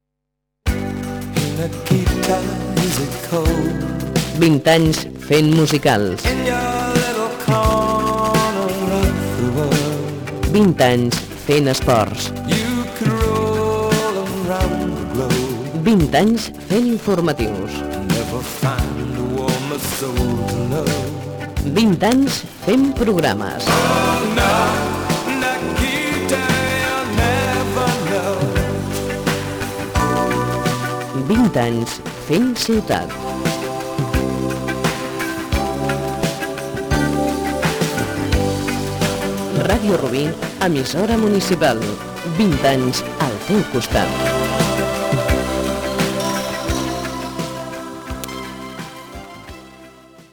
Indicatiu dels 20 anys de l'emissora